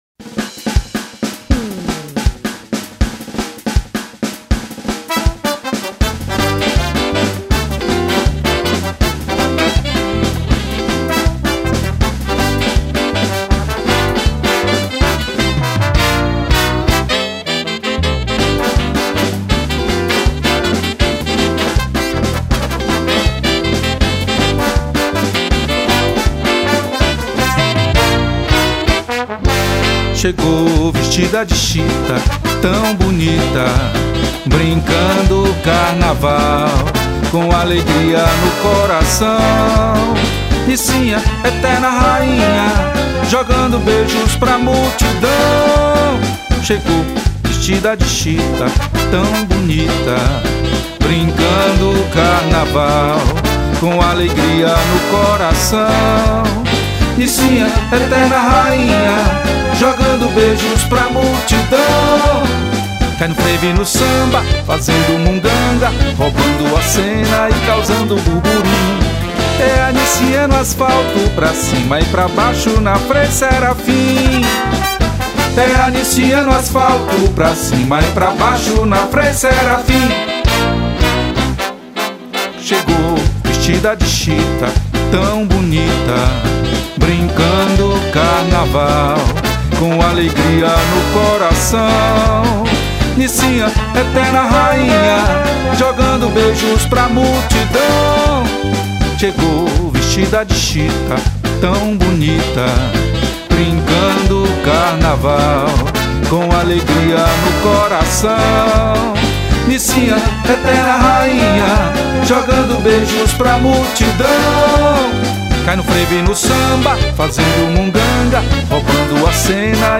592   03:53:00   Faixa:     Frevo